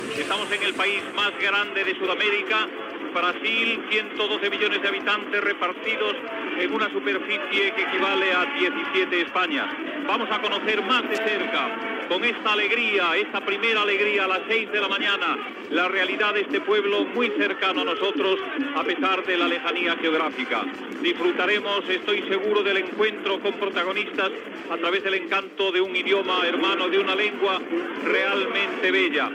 Presentació inicial del programa que es fa des del Brasil
Info-entreteniment